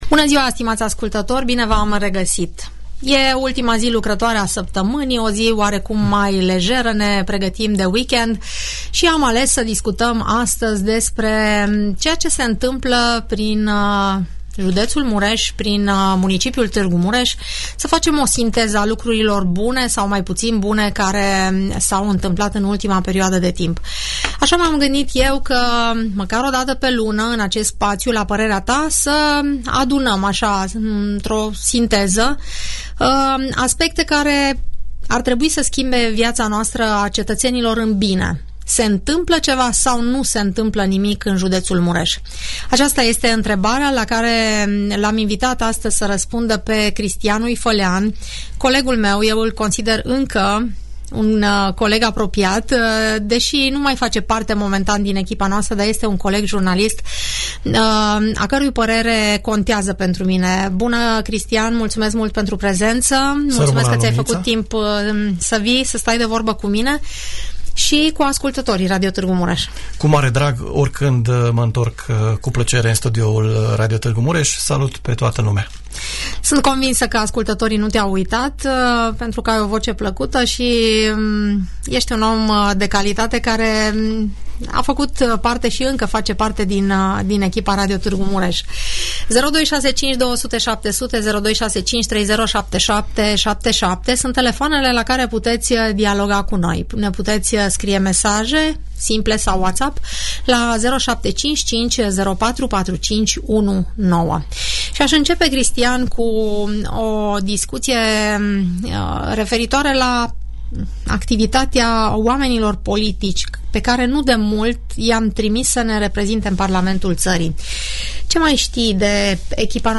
O radiografie a tot ceea ce s-a mai petrecut în ultima perioadă de timp în viața economică, politică sau administrativă a județului Mureș, poți urmări în emisiunea "Părerea ta". Ascultă la Radio Tg. Mureș discuția